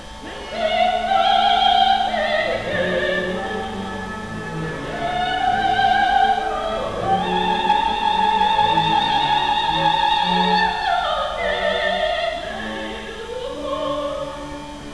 Donna Anna , Don Giovanni, Oxford and Cambridge Music Club, London, UK